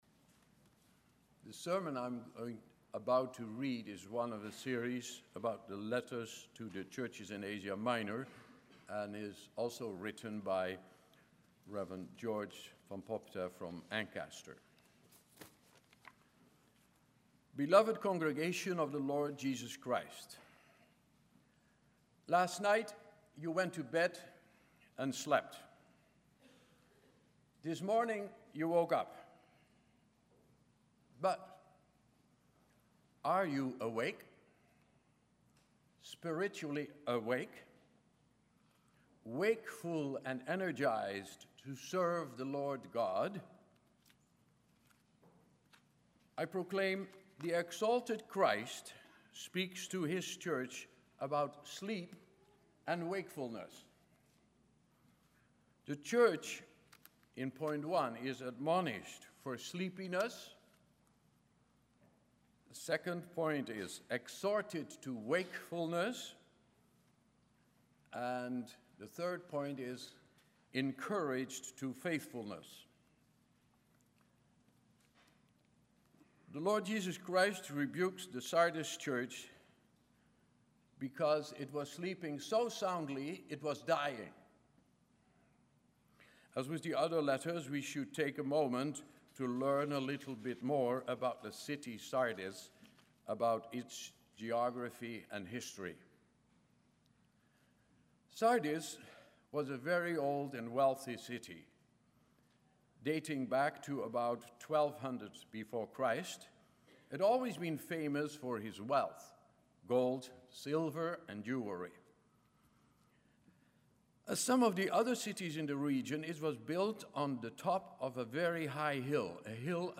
Sermon
Service Type: Sunday Morning